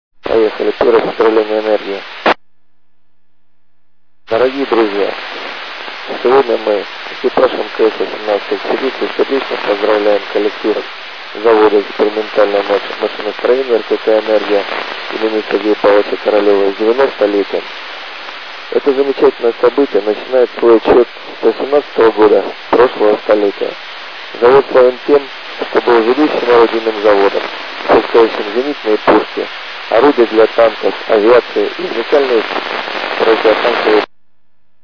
Начало » Записи » Записи радиопереговоров - МКС, спутники, наземные станции